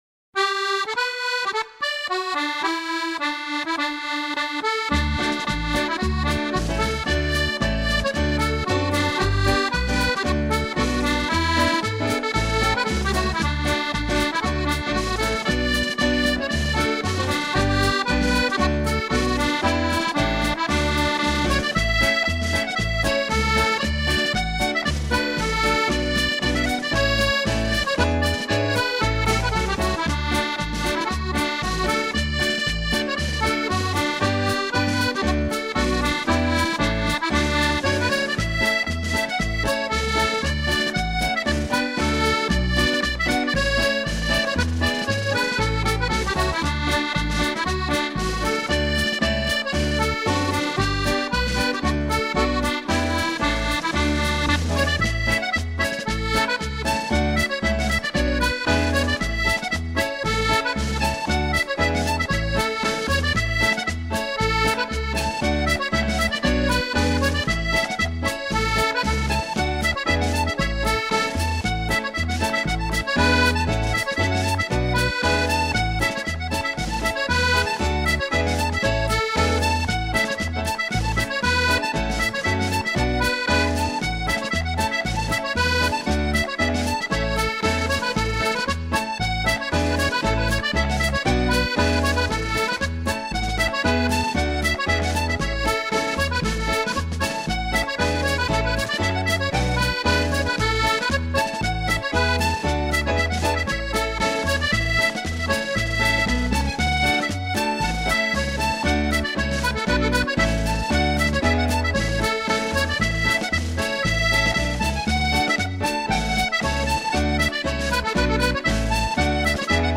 Genre: World Pop.